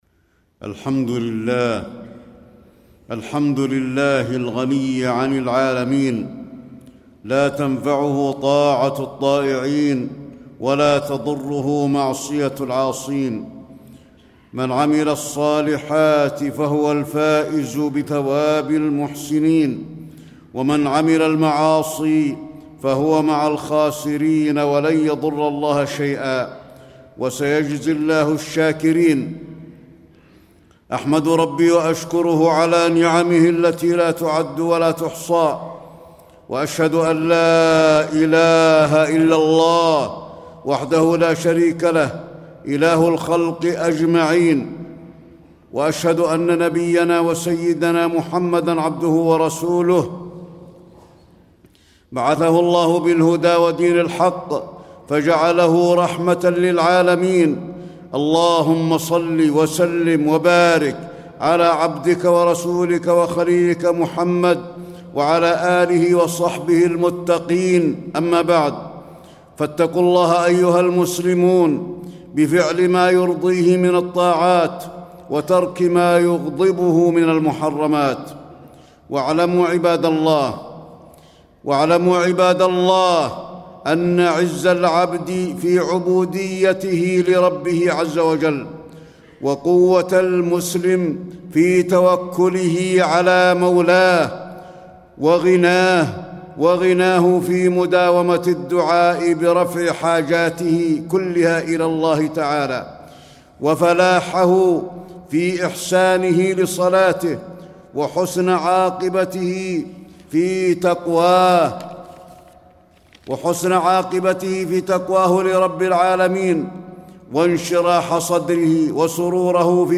تاريخ النشر ١٧ صفر ١٤٣٥ هـ المكان: المسجد النبوي الشيخ: فضيلة الشيخ د. علي بن عبدالرحمن الحذيفي فضيلة الشيخ د. علي بن عبدالرحمن الحذيفي العز في العبودية لله